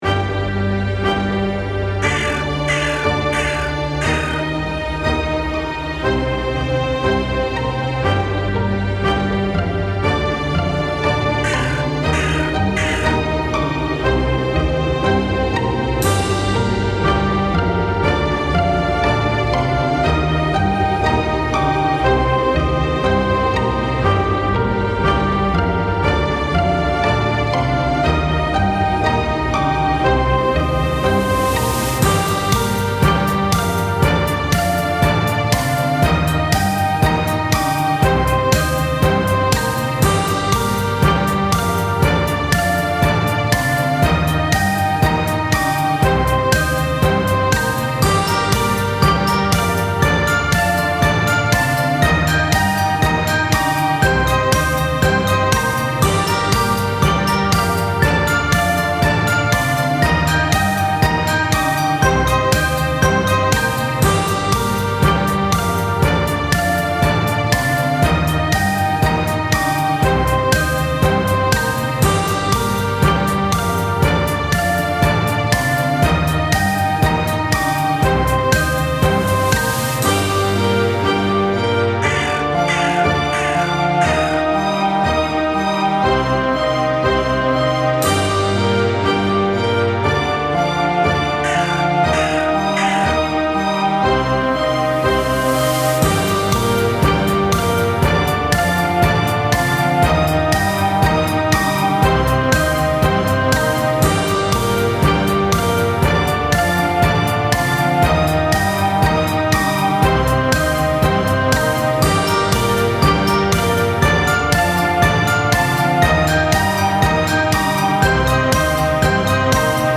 The atmosphere and setting of the survival shooter game is a cartoony, spooky zombie survival game, and I wanted to capture that within the music. I had a lot of inspiration for this track from a large amount of Halloween music and spooky cartoon music and I’m sure you’ll be able to see those references within this piece. Some elements of the standalone track was removed for the version used in the game, such as the crow samples, and a few other ambient sounds.